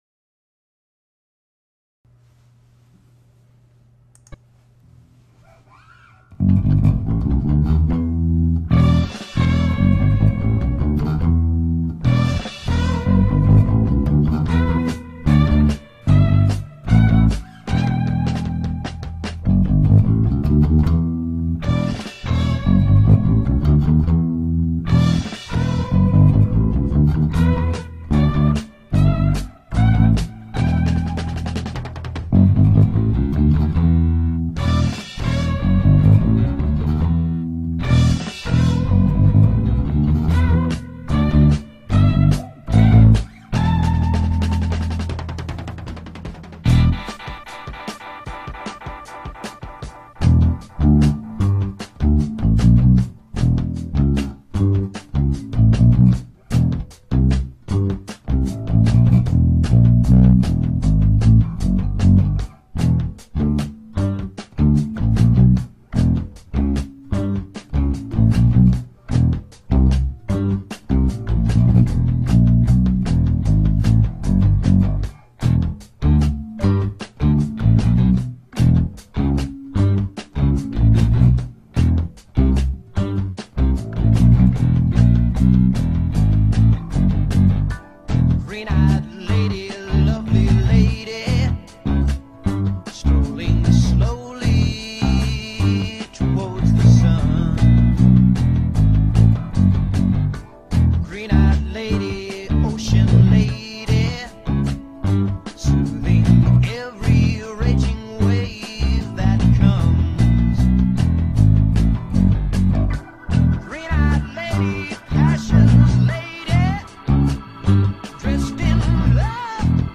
1970s psychedelic rock